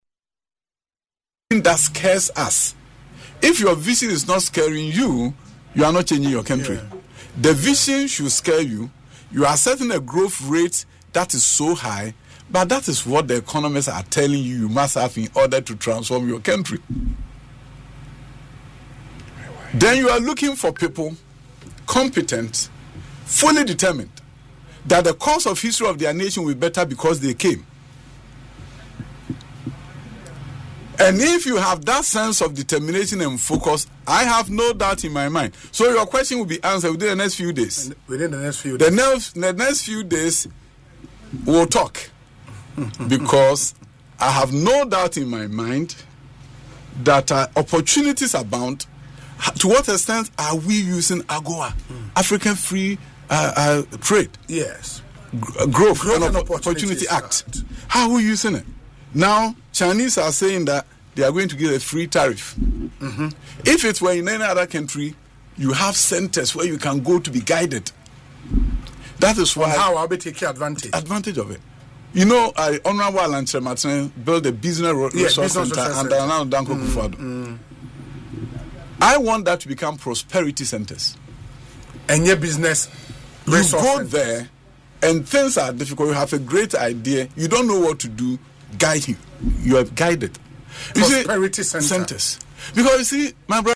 In an interview on Asempa FM’s Ekosii Sen, Dr. Adutwum declared that he has no doubt he possesses the qualities needed to drive Ghana’s development.